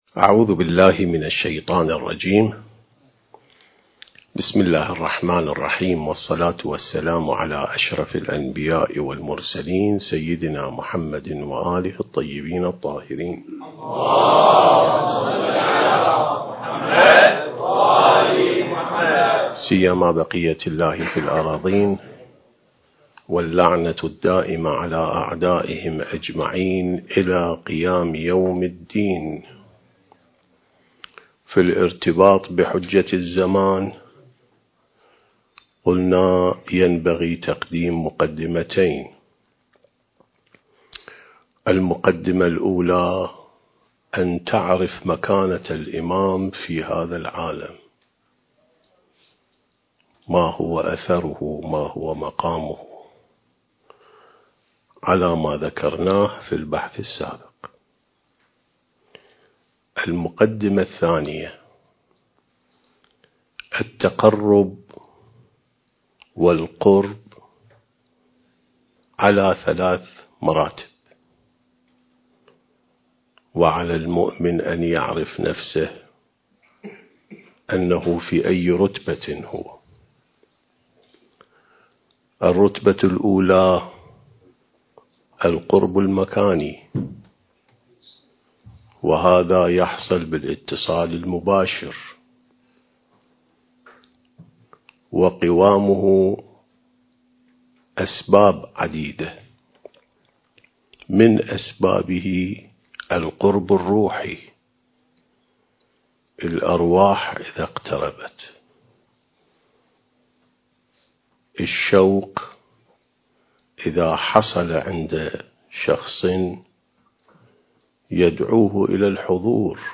عين السماء ونهج الأنبياء سلسلة محاضرات: الارتباط بالإمام المهدي (عجّل الله فرجه)/ (3)